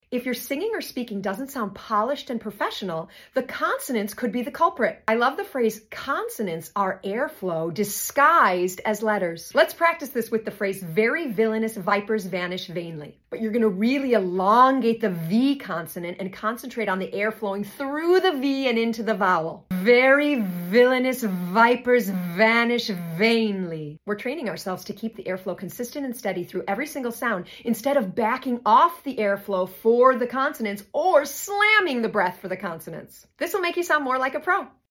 We’re going to elongate the V sounds and focus on letting your airflow move steadily through those consonants into the vowels.